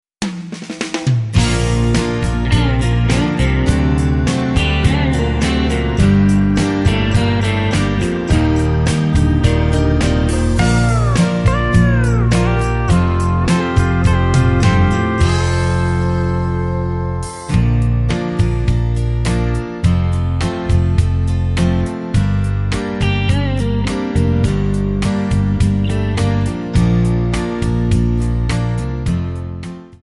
Backing track Karaoke
Country, 2000s